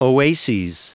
Prononciation du mot oases en anglais (fichier audio)
Prononciation du mot : oases